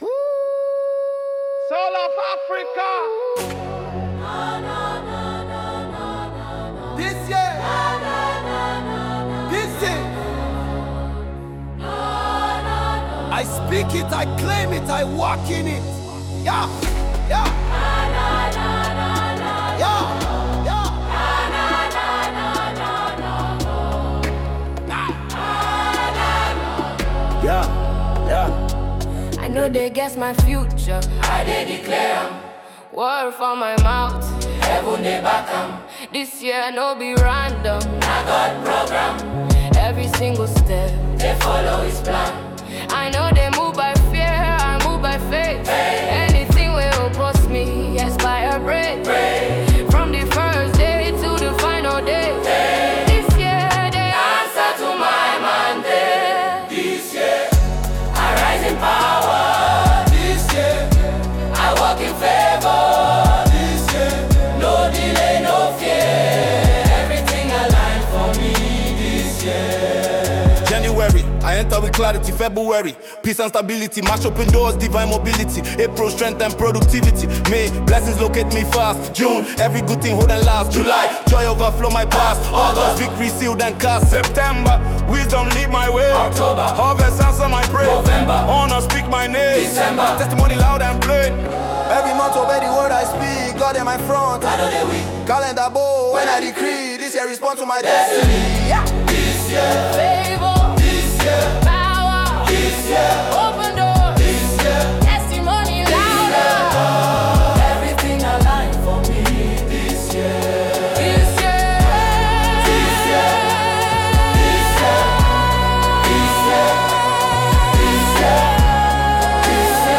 Afro-Gospel Choir Group